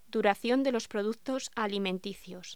Locución: Duración de los productos alimenticios
voz